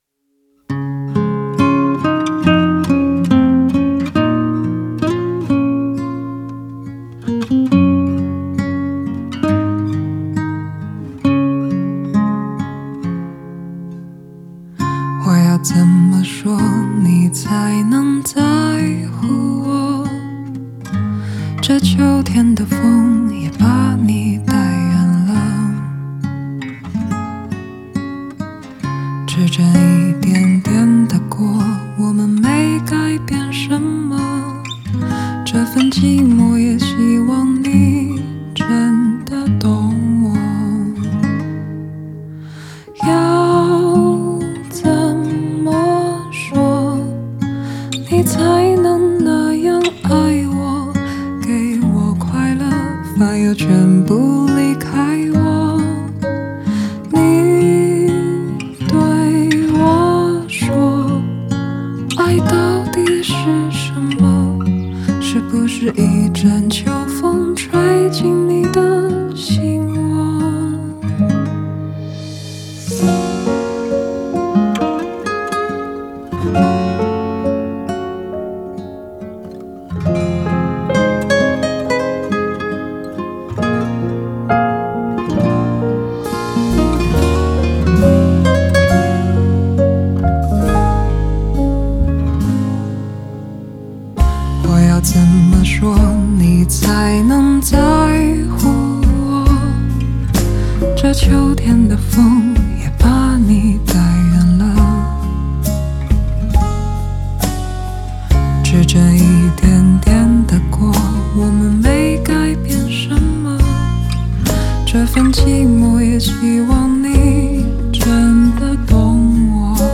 Ps：在线试听为压缩音质节选，体验无损音质请下载完整版
吉他guitar
鼓drums
贝斯bass